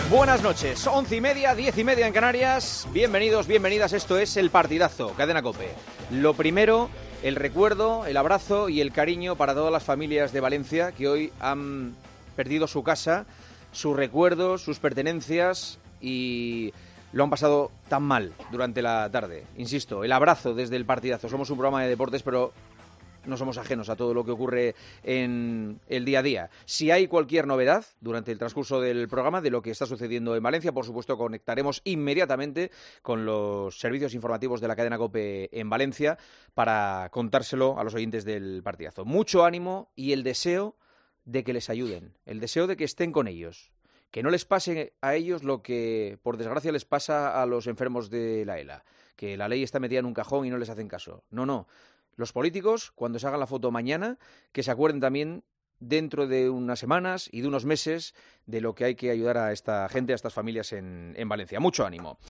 Este jueves el día en España ha estado marcado por el terrible suceso en la ciudad del Turia y el comienzo de El Partidazo de COPE fue para todos los que lo han sufrido
Juanma Castaño quiso acordarse nada más comenzar el programa de los afectados por el devastador incendio que ha asolado un edificio de viviendas y locales comerciales en Valencia. El presentador ha destacado lo que espera que hagan los políticos.